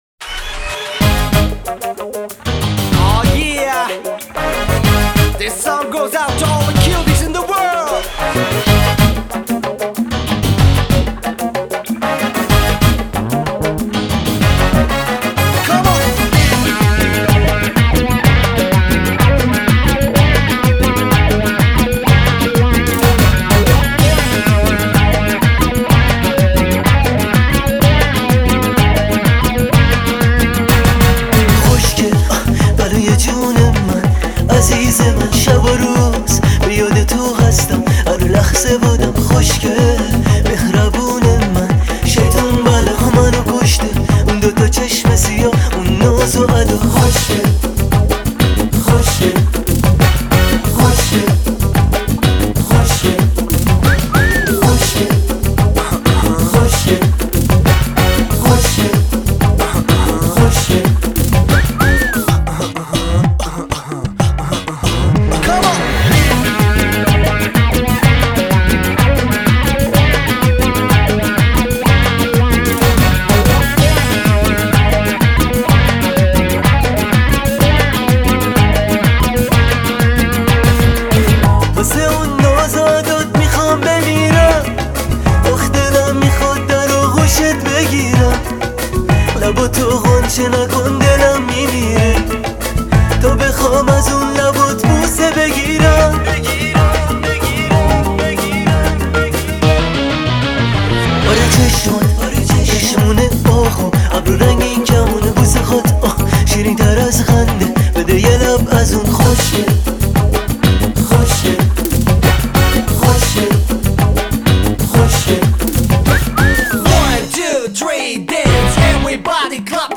شاد و پرانرژی